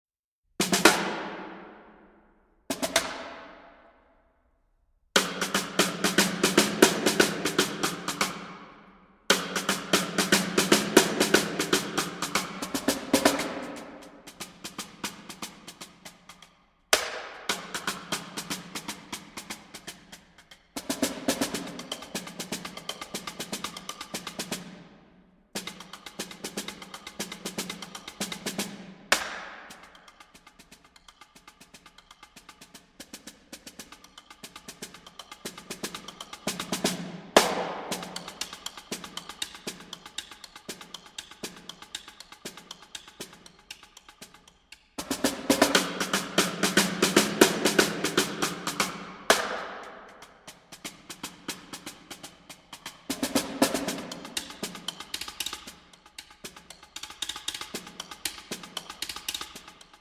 Marimba
Vibraphone
Timpani